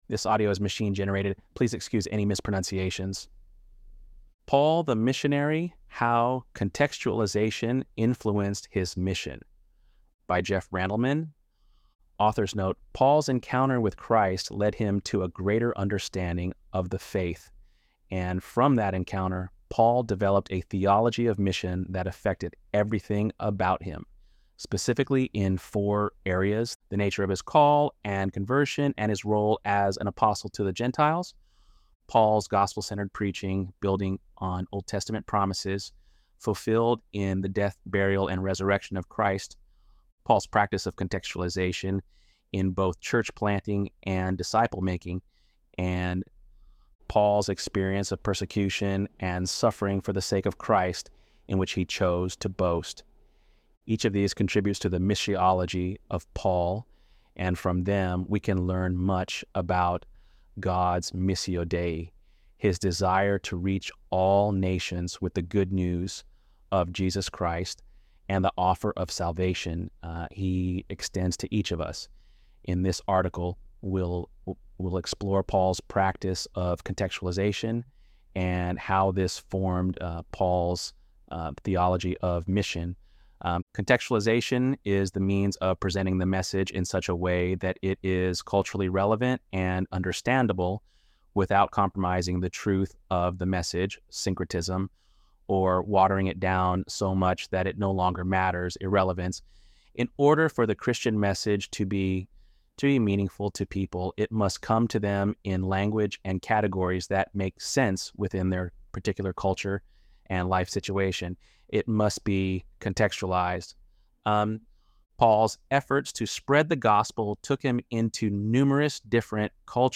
ElevenLabs_7.31_Paul.mp3